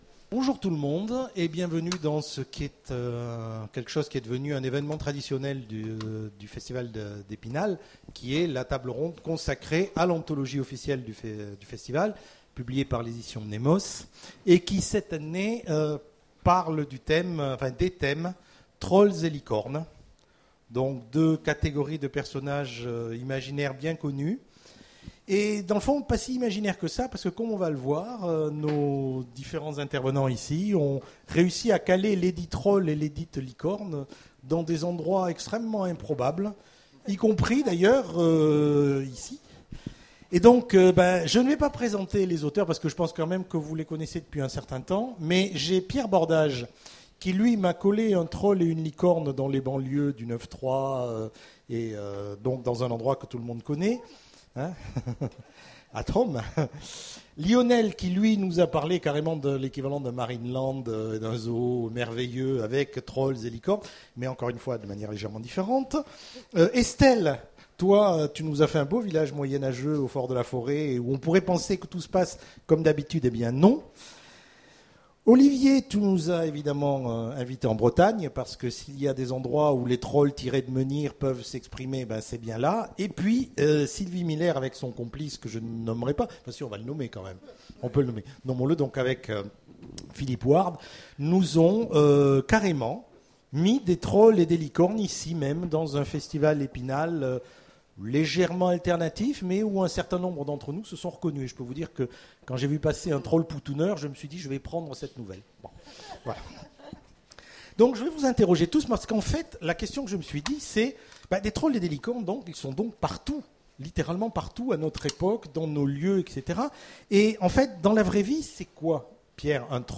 Imaginales 2015 : Conférence Trolls & Licornes